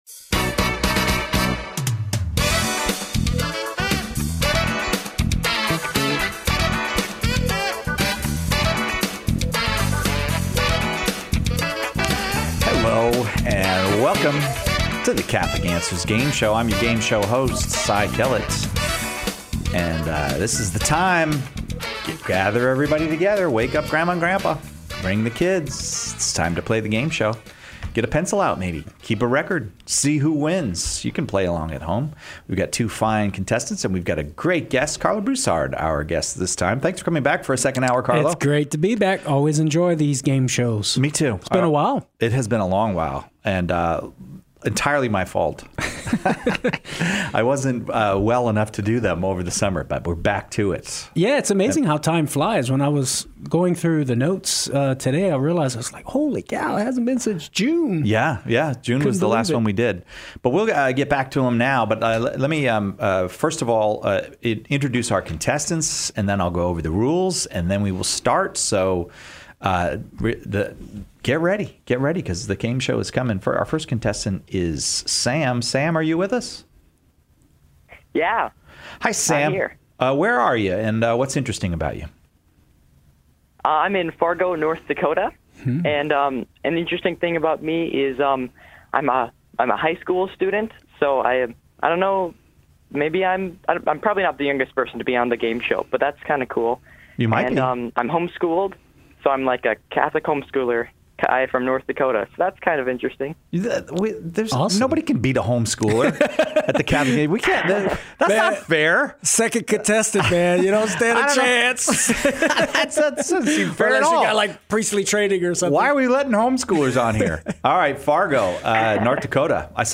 Another installment of the Game show where to listeners go head to head to answer so tough apologetical questions!